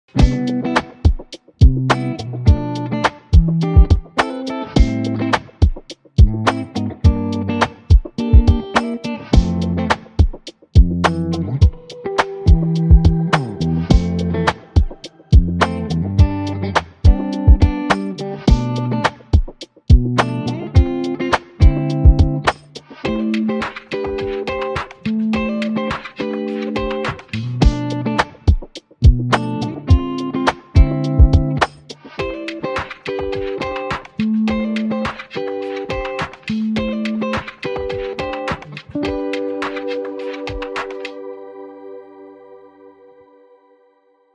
Guitar Li Fi Vibes Sound Effects Free Download